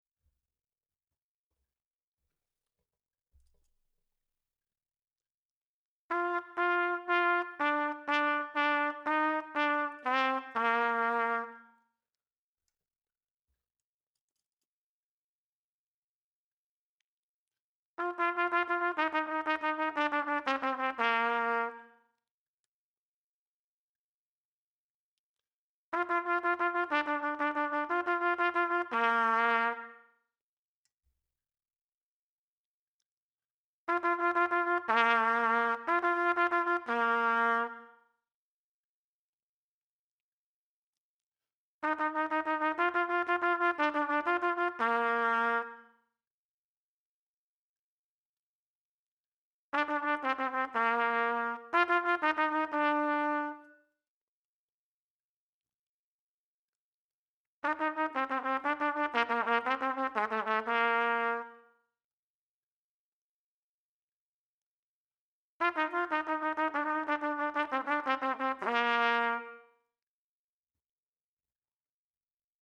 Triple Tonguing Exercises